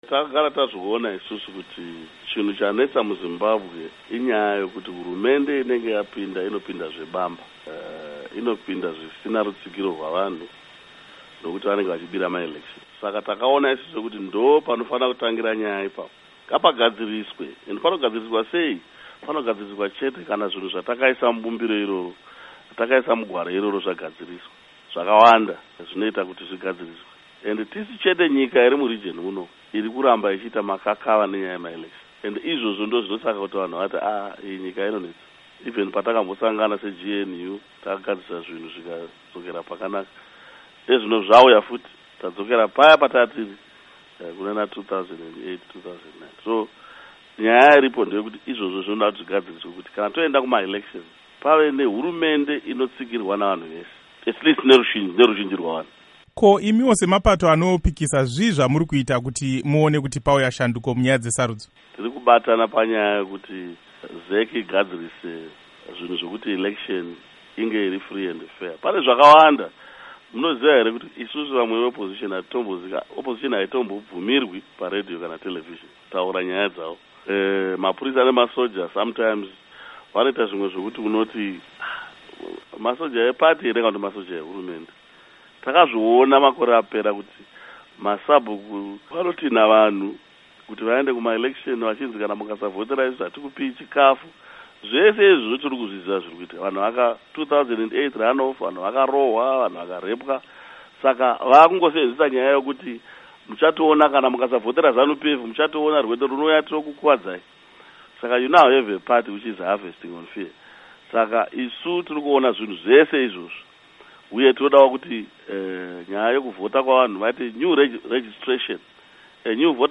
Hurukuro naVaMorgan Tsvangirai